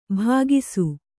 ♪ bhāgisu